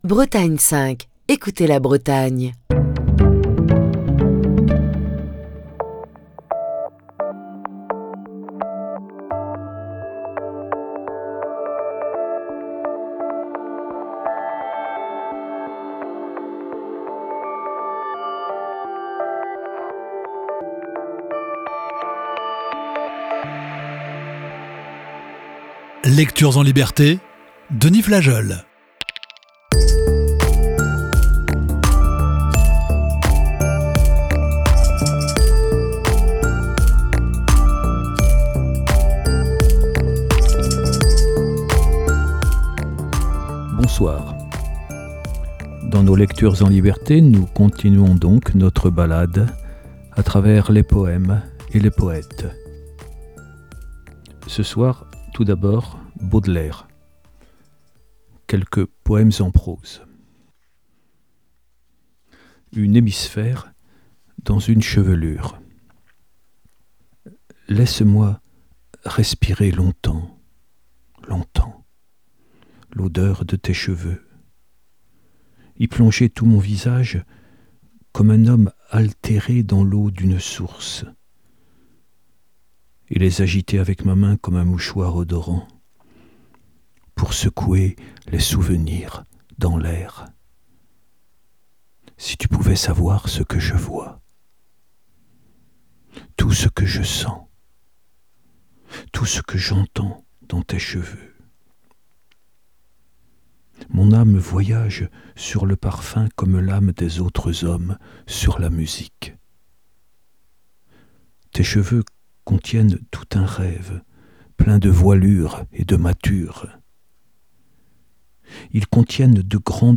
Émission du 7 février 2024.